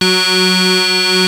OSCAR 15 F#3.wav